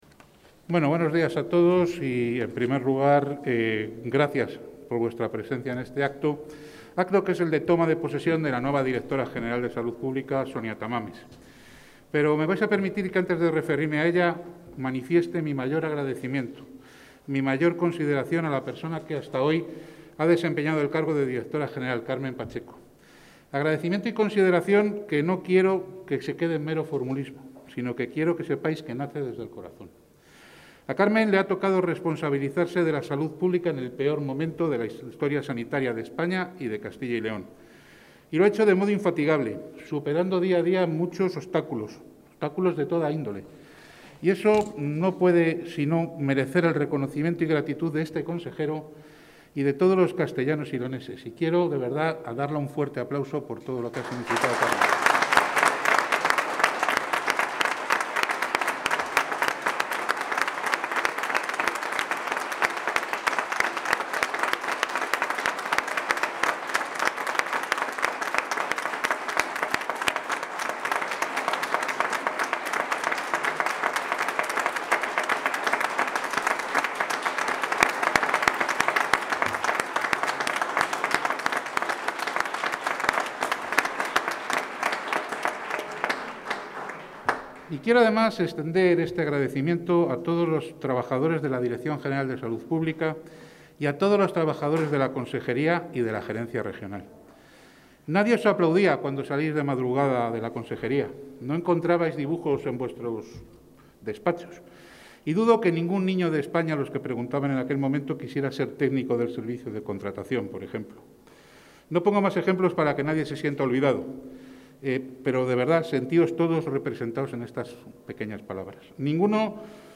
Intervención del consejero de Sanidad.
El consejero de Sanidad, Alejandro Vázquez, ha presidido hoy la toma de posesión de la nueva directora general de Salud Pública, Sonia Tamames.